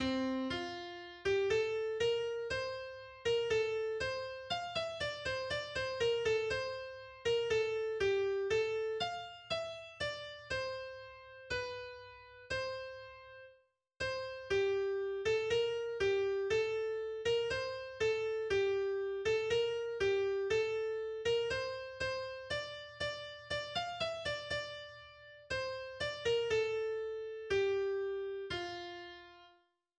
volkstümliches Weihnachtslied